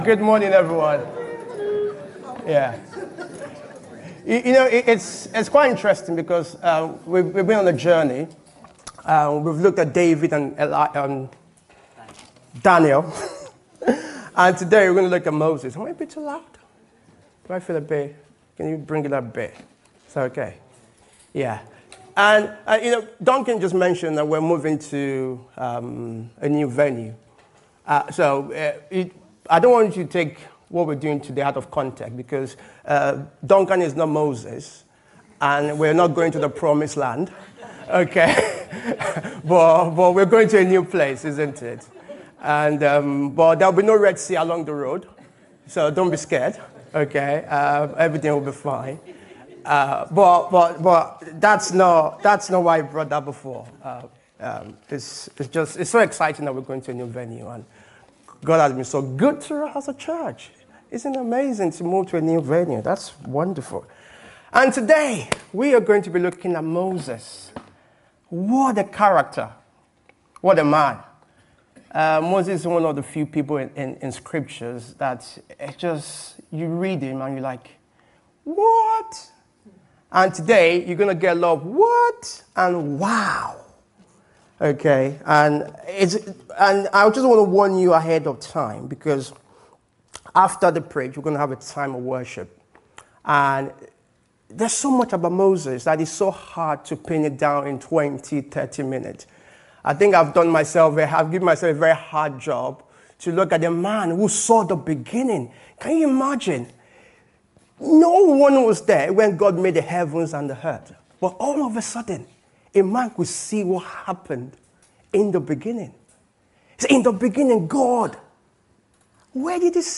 This sermon explores the faithfulness demonstrated across Moses’ life in choosing to pursue God above everything else.